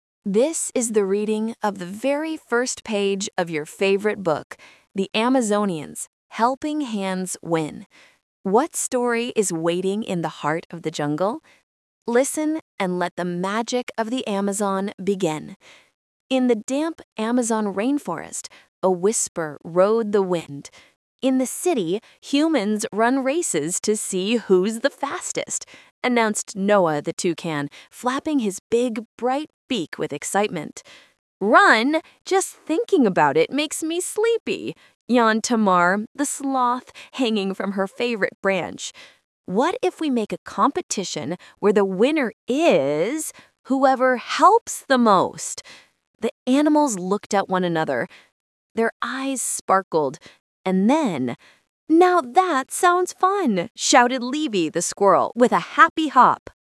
Readings (audio)